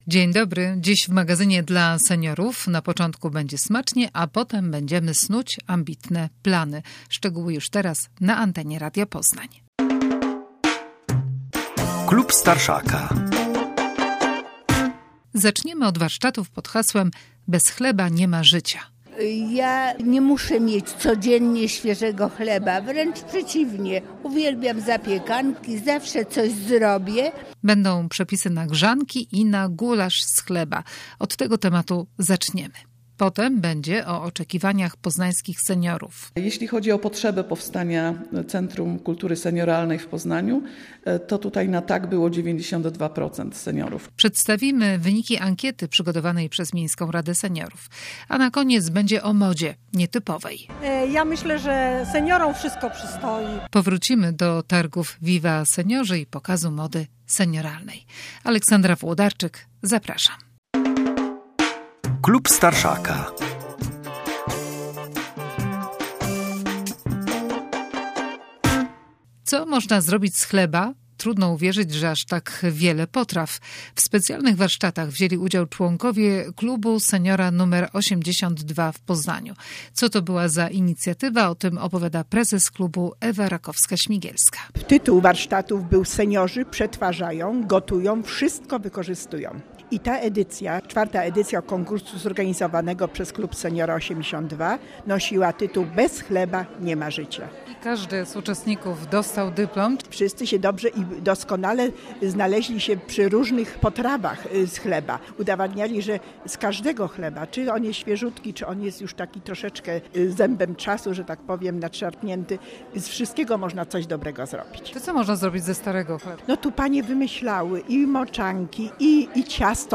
W magazynie relacja z podsumowania warsztatów. Potem o wynikach ankiety, przygotowanej przez Miejską Radę Seniorów w Poznaniu.
Na koniec relacja z pokazu mody senioralnej, który na targi Viva Seniorzy przygotowali członkowie Klubu Seniora Winogrady.